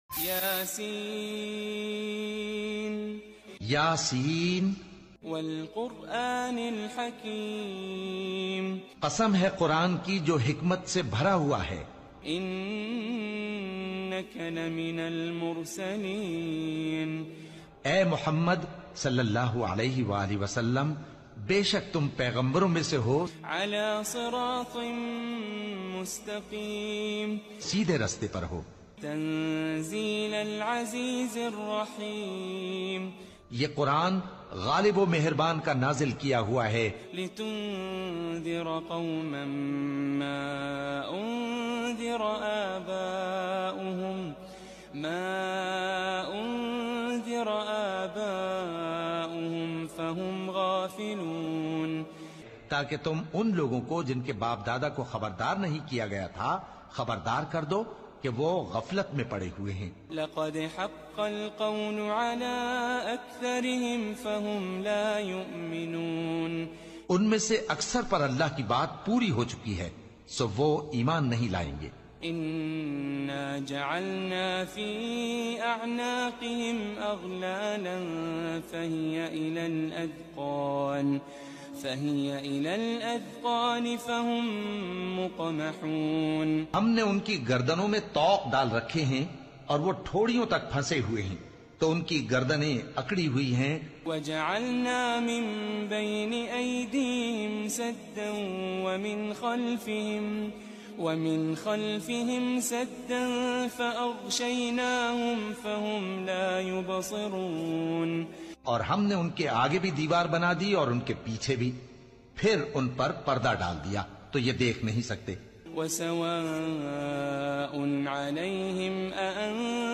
Very peaceful and beautiful voice